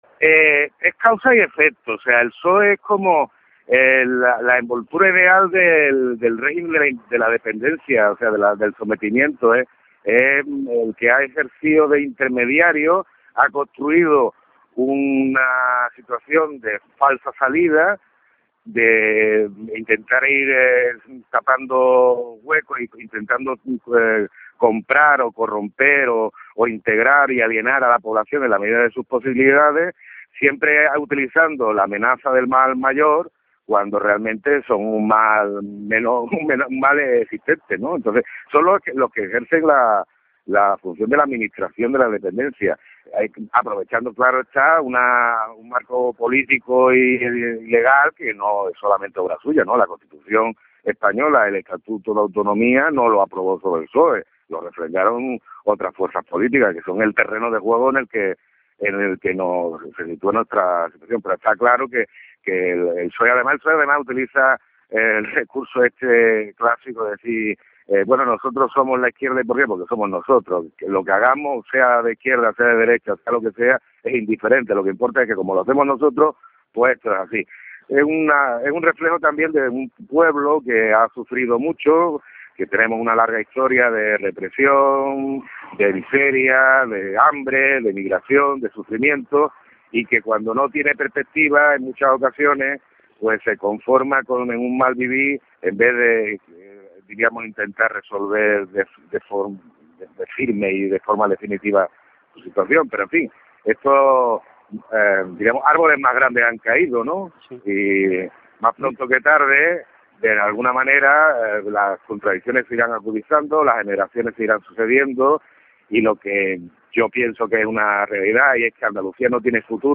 Escuche parte de la entrevista